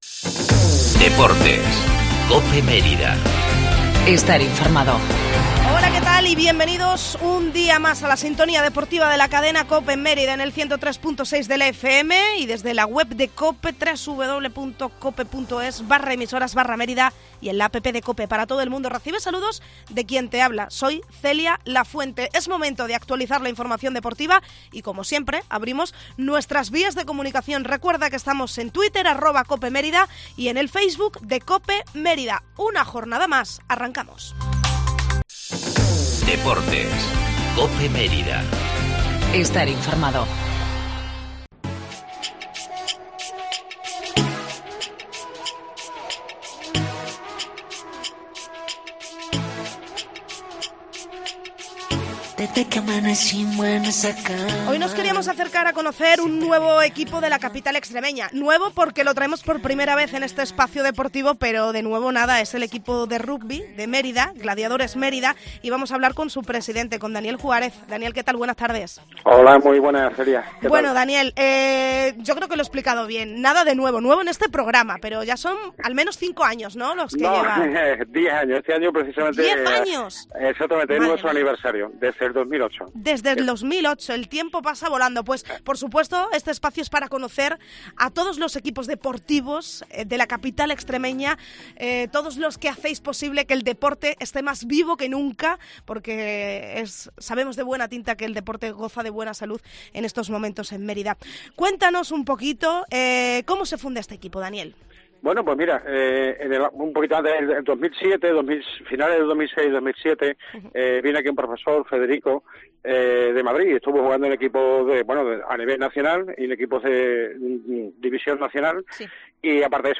DXT Cope Mérida 20/11/2018 entrevista a Gladiadores Mérida